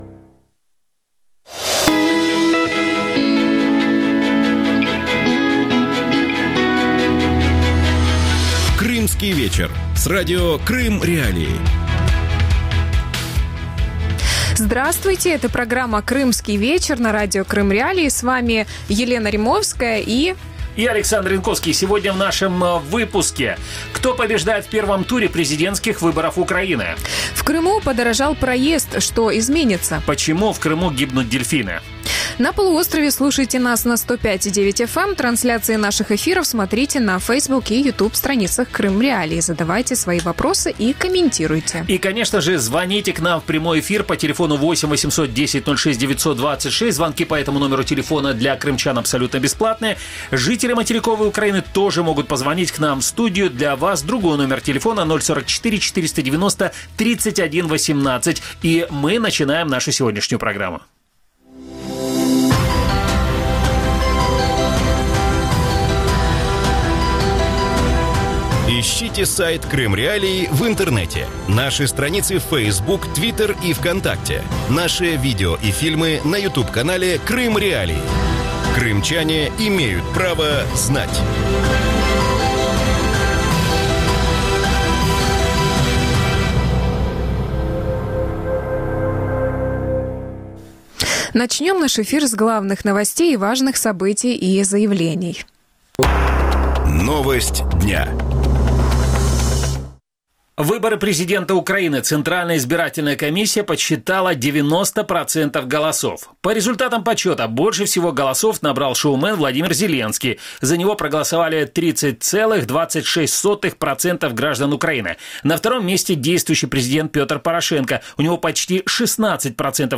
Повлияла ли Россия на результаты выборов в Украине? Гости эфира
российский политический обозреватель
украинский политолог.